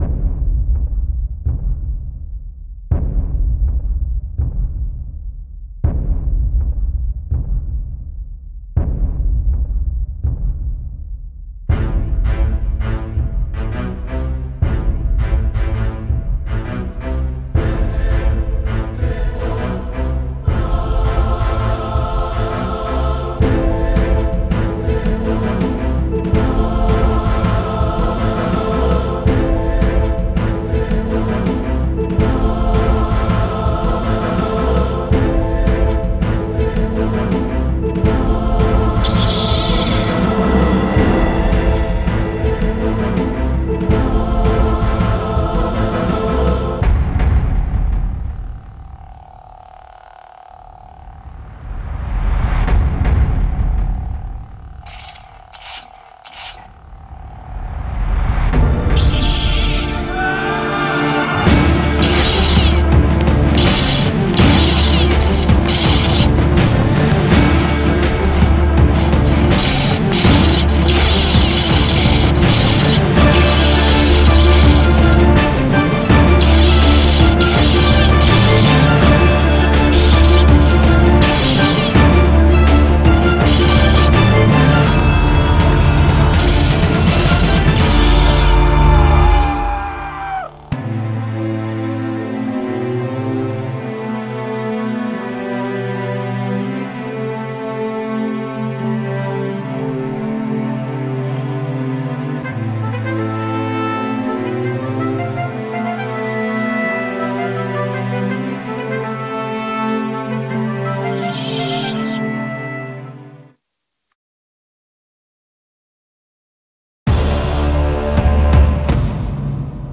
배경음악을 직접 만들어 들으며 기다린다
Music Maker Jam이라는 앱과 고유의 사운드 이펙으로 만든 광선검 싸움 배경음악.